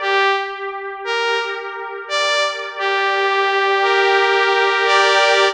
Mob Strings.wav